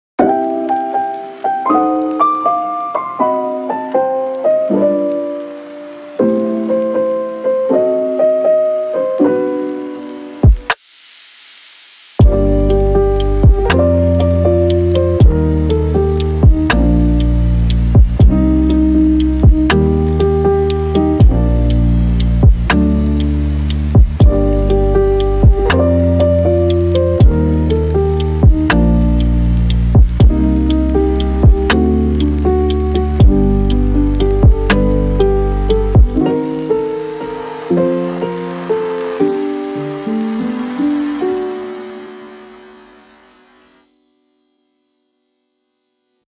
雨音が混じる哀愁漂うトラックは、切ない回想シーンや、静かな夜の語り、レトロな雰囲気の演出に最適です。
1.77 MB Lo-fi Emo Nostalgic Piano Rain よかったらシェアしてね！